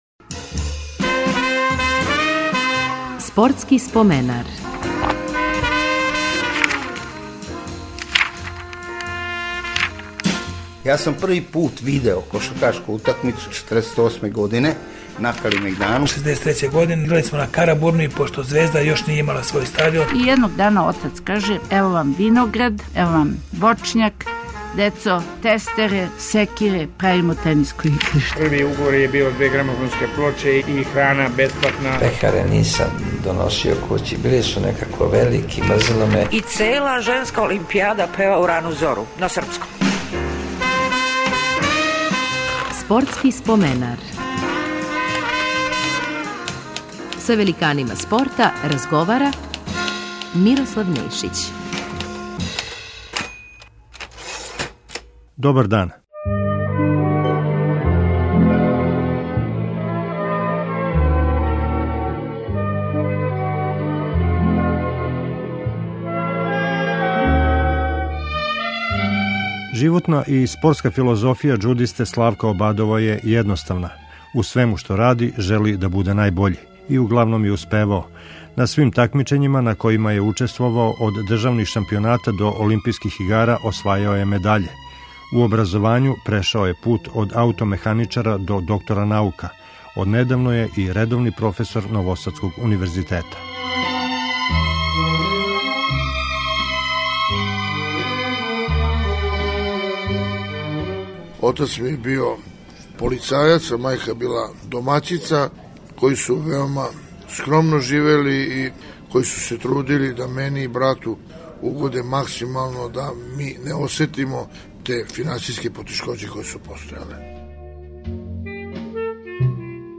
Гост емисије је наш најбољи џудиста свих времена Славко Обадов.